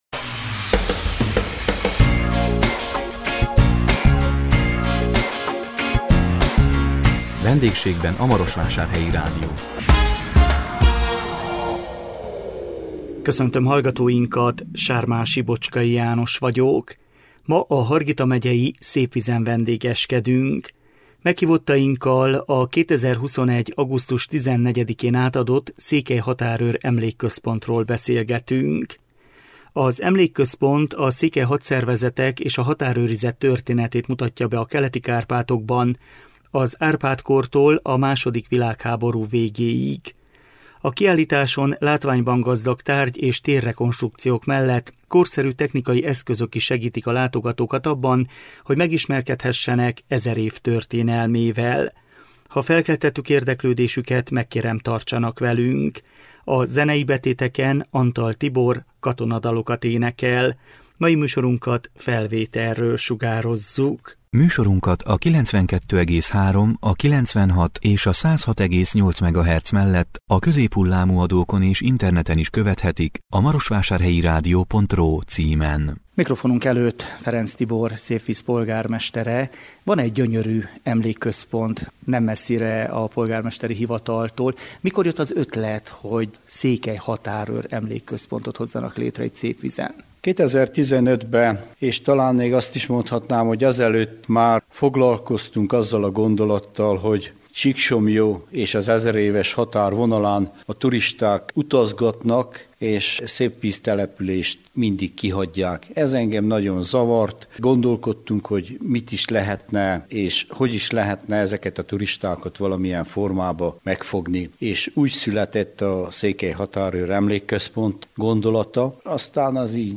A 2022 január 20-án jelentkező VENDÉGSÉGBEN A MAROSVÁSÁRHELYI RÁDIÓ című műsorunkkal a Hargita megyei Szépvízen vendégeskedtünk. Meghívottainkkal a 2021 augusztus 14-én átadott Székely Határőr Emlékközpontról beszélgettünk. Az emlékközpont a székely hadszervezetek és a határőrizet történetét mutatja be a Keleti-Kárpátokban, az Árpád-kortól a második világháború végéig.